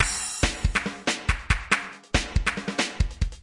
鼓的循环 4
描述：鼓循环拍打